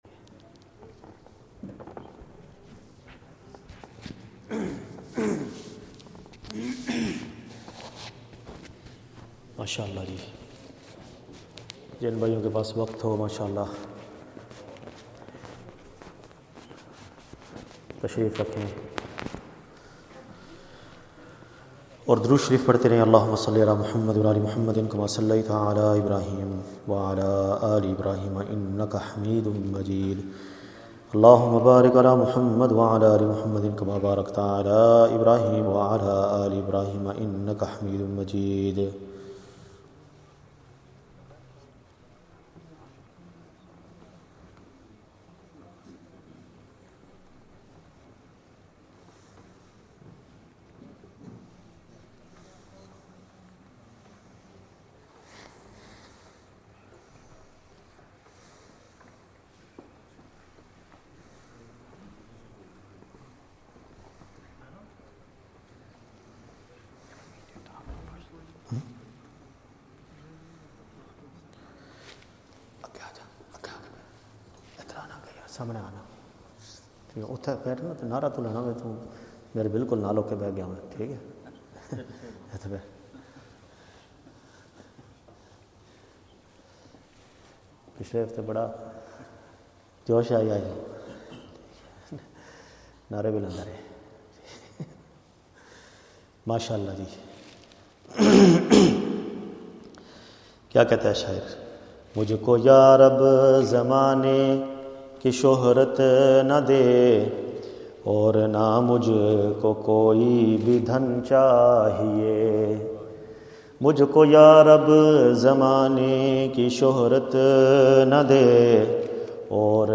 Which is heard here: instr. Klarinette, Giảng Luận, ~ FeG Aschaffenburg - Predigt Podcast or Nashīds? Nashīds